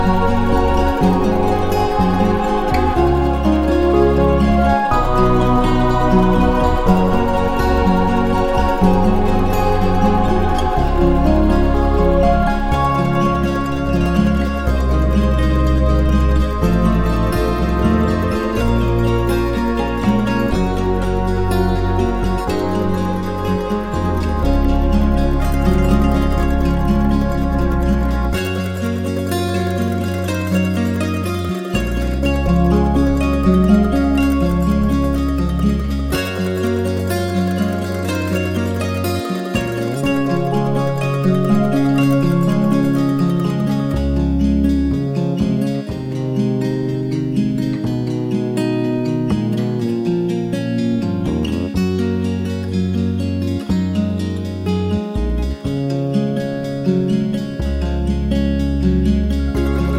no Backing Vocals Soundtracks 3:13 Buy £1.50